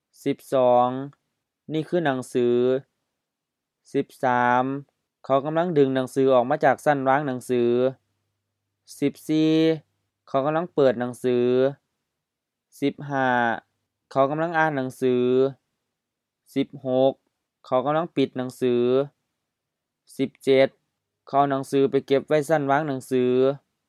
Notes: sentence-final: often with rising tone which is likely a Thai influence